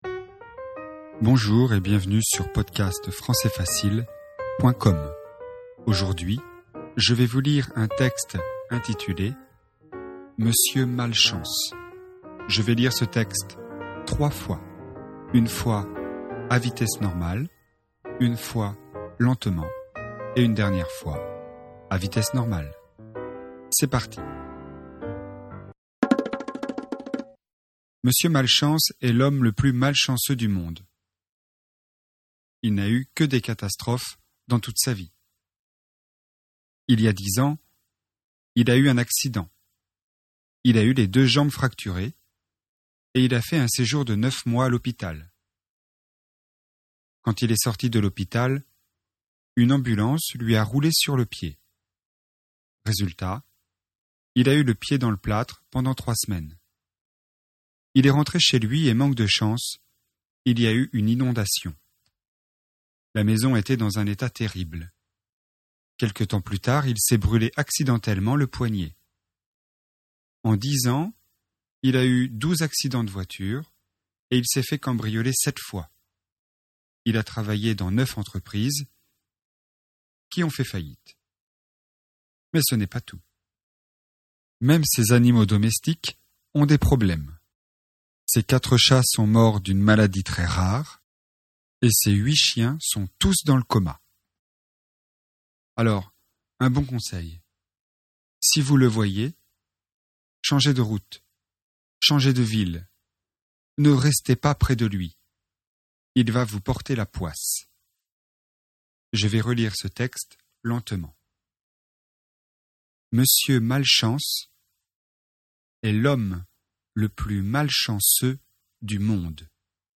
Texte FLE et audio, niveau intermédiaire (A2).